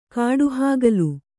♪ kāḍu hāgalu